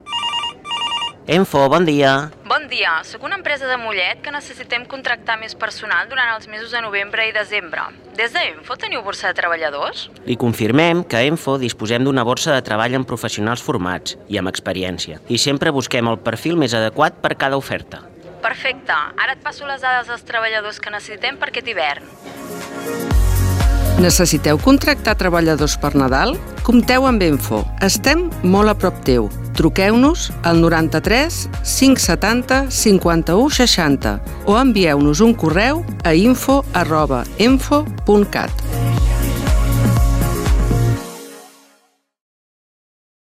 I avui volem compartir amb vosaltres la primera de les falques que hem gravat a Ràdio Mollet. L’objectiu d’aquesta és fer difusió de la borsa de treball d’EMFO en un període on les contractacions incrementen, el Nadal.